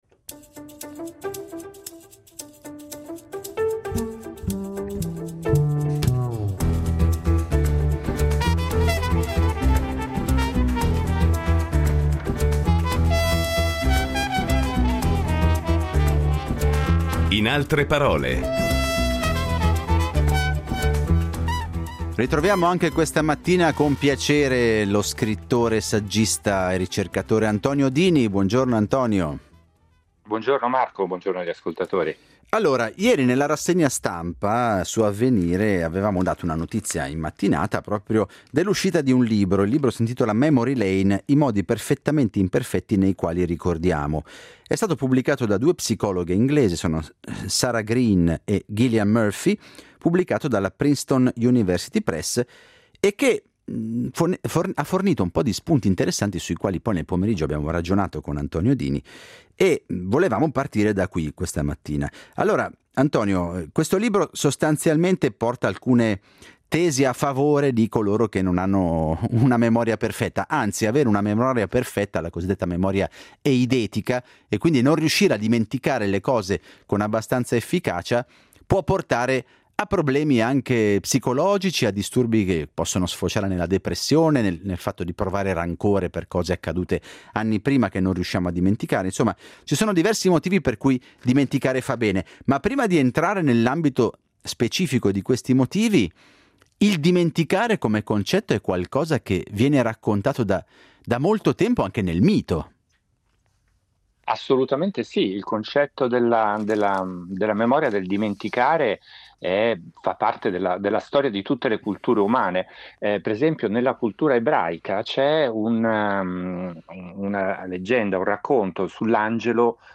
Incontro con l’autore, saggista e scrittore